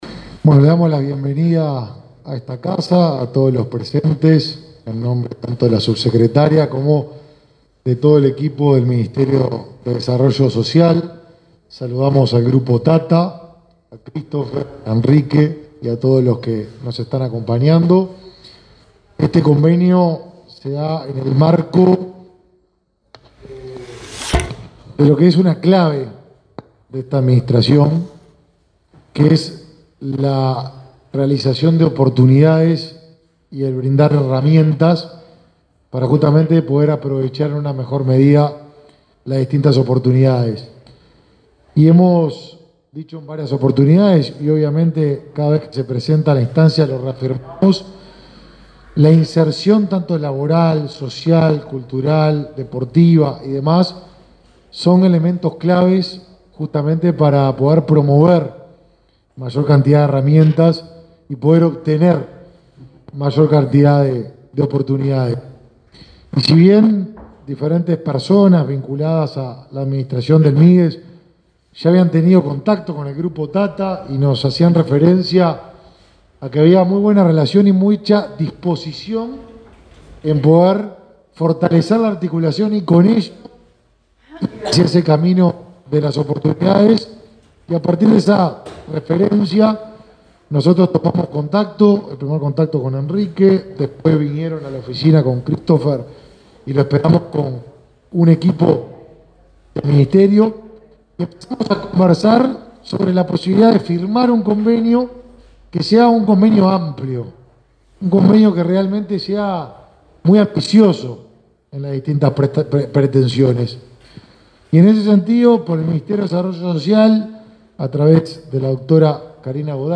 Declaraciones del ministro de Desarrollo Social, Martín Lema, en sede del Mides